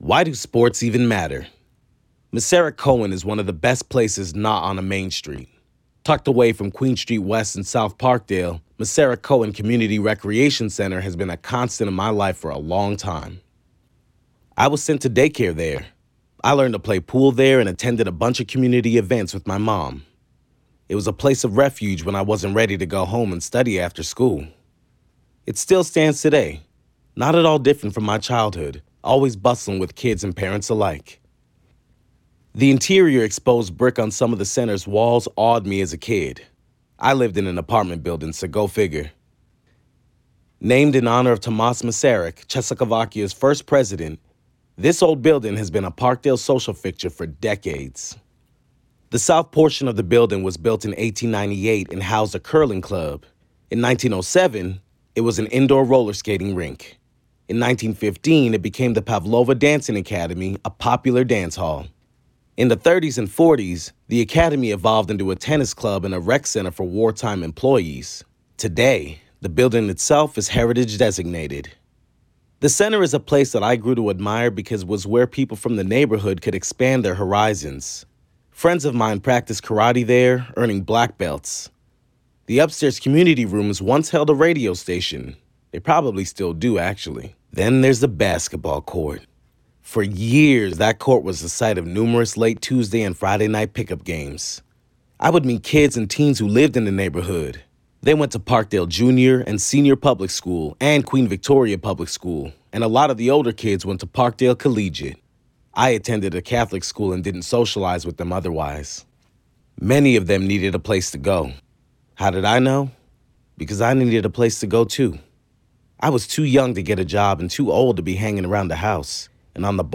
Narration - EN